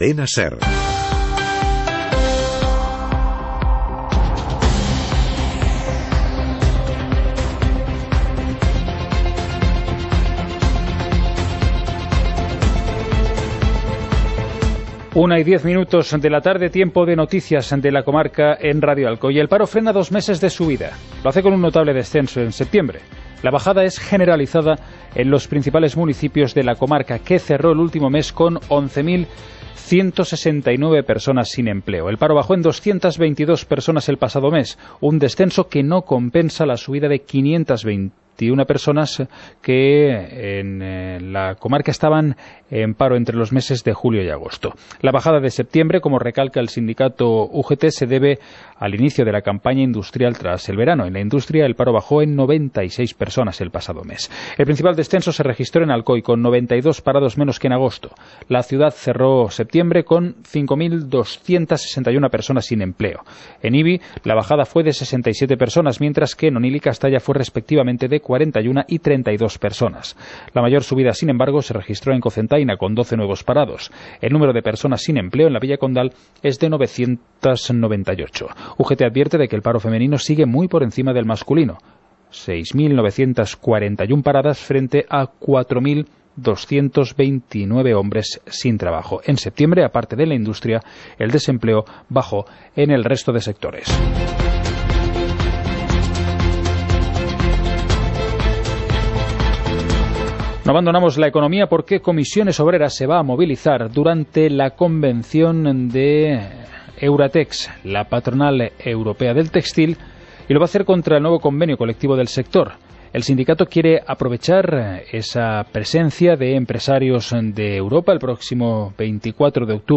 Informativo comarcal - jueves, 04 de octubre de 2018